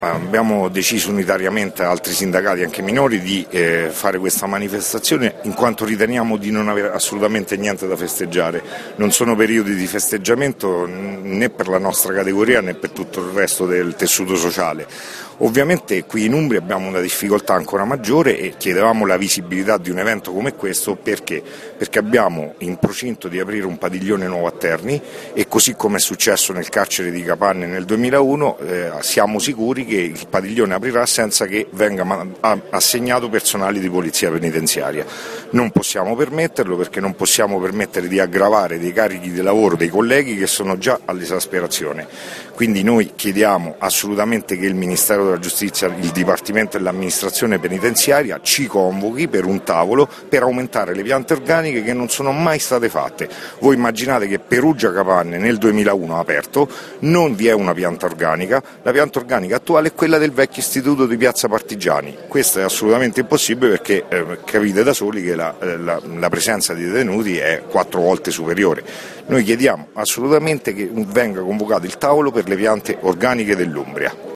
AUDIO: INTERVISTA
La protesta In piazza Duomo, a margine della cerimonia, erano presenti tutte le rappresentanze sindacali della polizia penitenziaria.